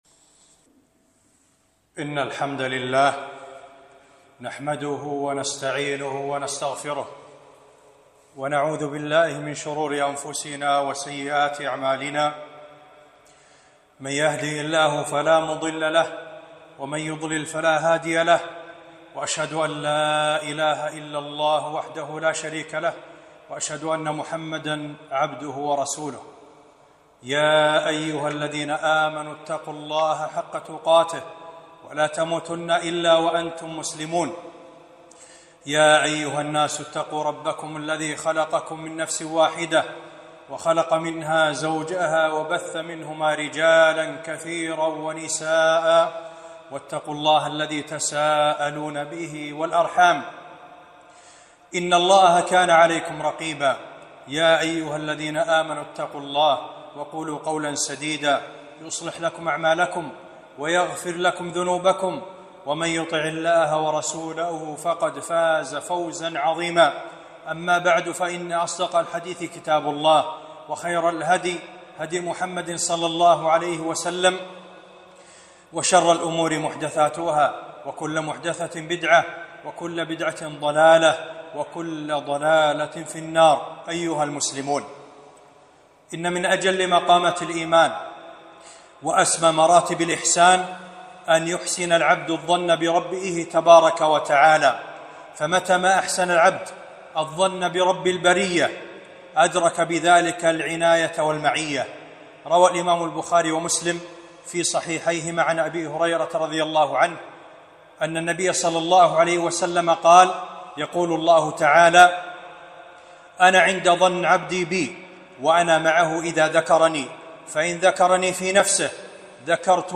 خطبة - حسن الظن بالله